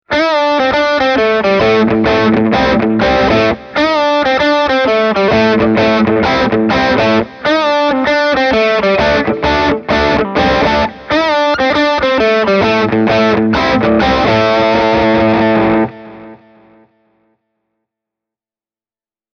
Putting good descriptions to sounds is always rather hard – so take a listen to the soundbites I recorded for you, using my Fender ’62 Telecaster Custom -reissue, as well as my Hamer USA Studio Custom. All delays and reverbs have been added at during mixdown.
Hamer – Blues-overdrive
hamer-e28093-blues-crunch.mp3